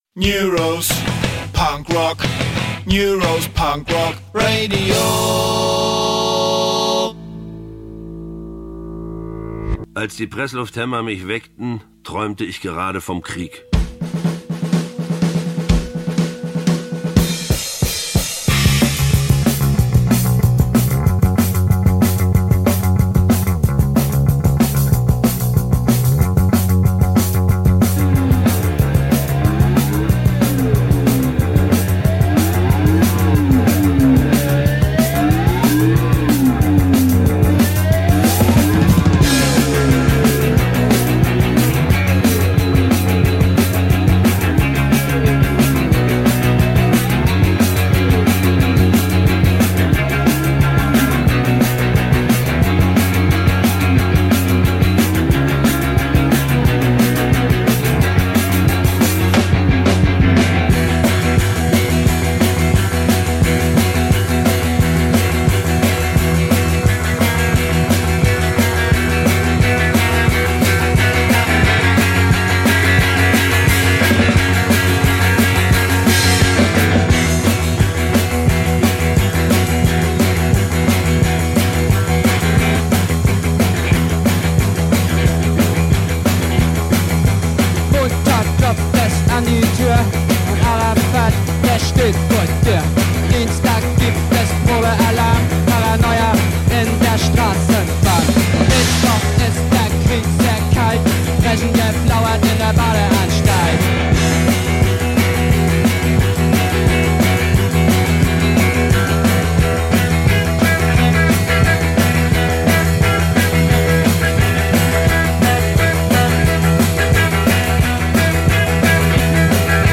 Heute auch mal wieder pünktlich: der wöchentliche Mix.